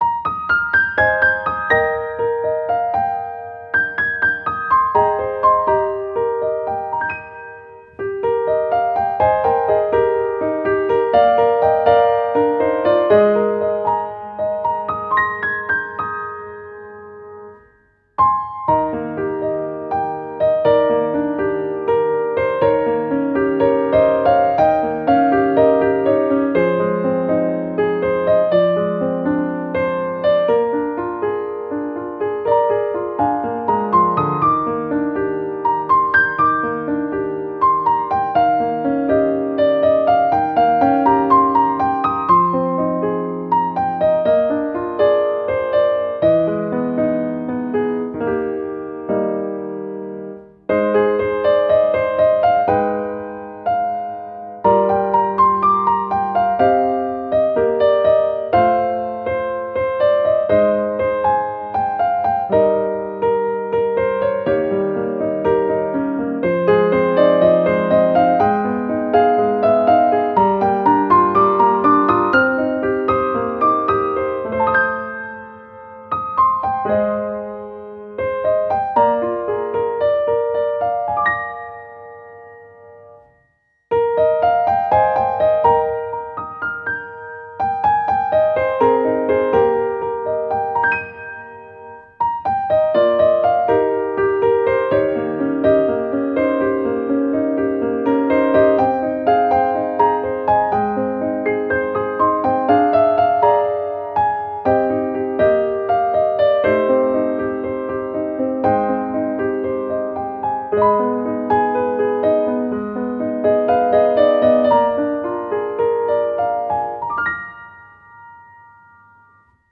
• 明るくほがらかなピアノ曲のフリー音源を公開しています。
ogg(R) 楽譜 涼しい ゆったり 夏 バラード
心地良い涼しさを届けるピアノバラード。